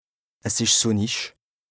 Français Dialectes du Bas-Rhin Dialectes du Haut-Rhin Page